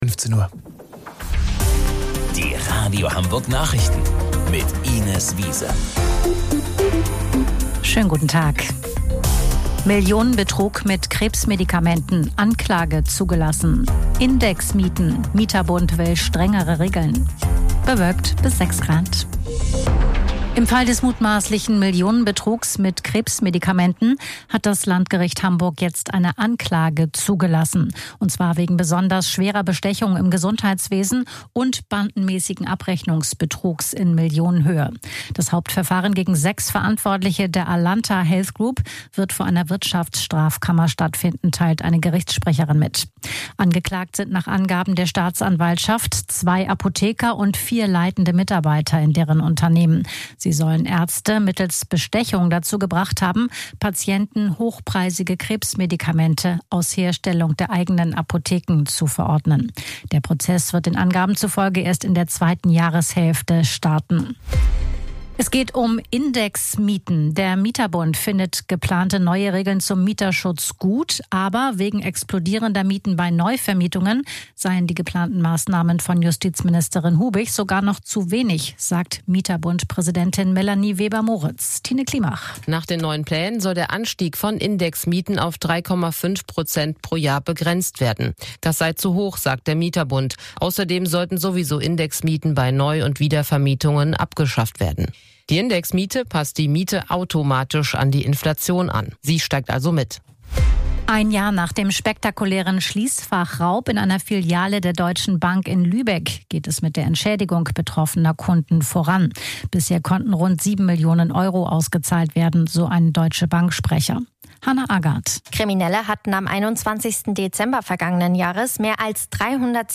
Radio Hamburg Nachrichten vom 22.12.2025 um 15 Uhr